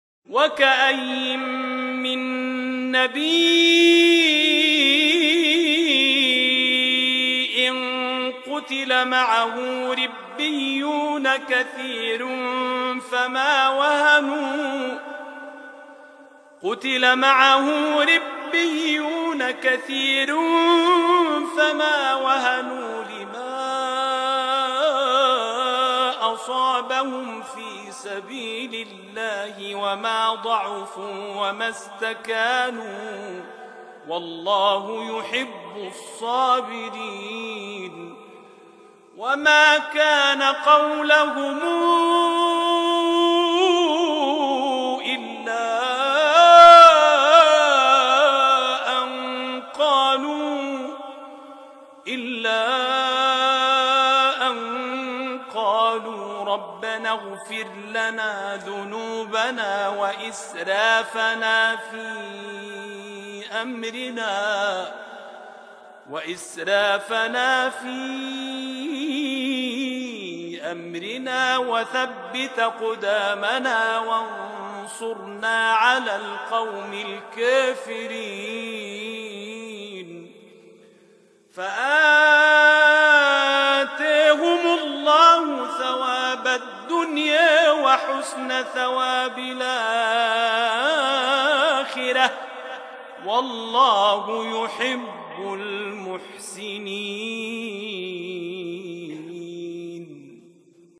تلاوة محبرة من سورة آل عمران براوية ورش عن نافع